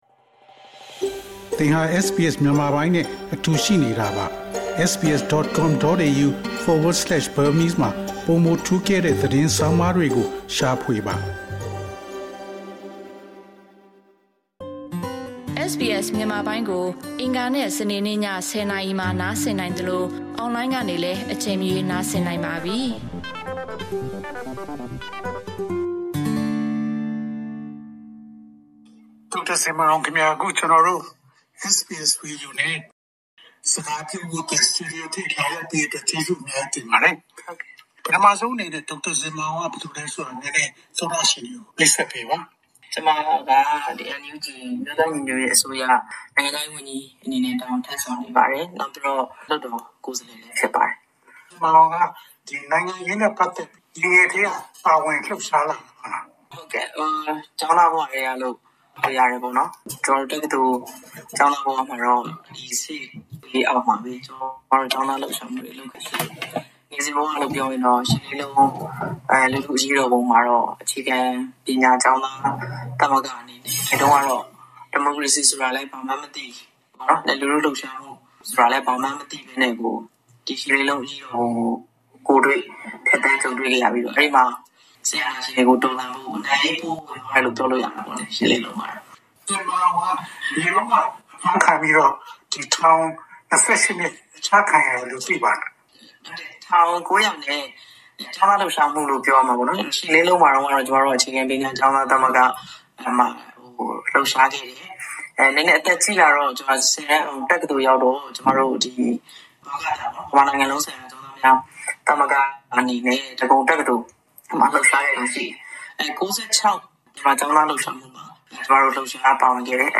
NUG နိုင်ငံခြားရေးဝန်ကြီး ဒေါ်ဇင်မာအောင် တွေ့ဆုံမေးမြန်းချက် အပိုင်း ၁
Daw Zin Mar Aung at SBS Studio